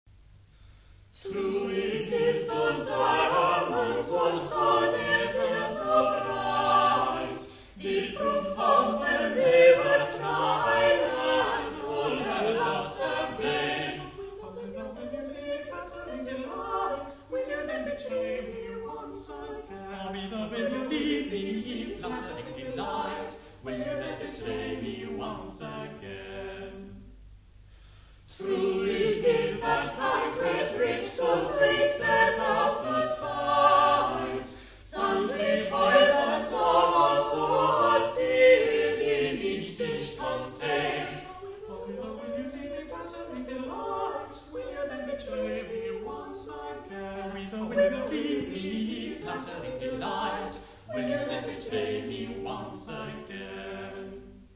To a French tune 'Où êtes vous allez mes belles amourettes'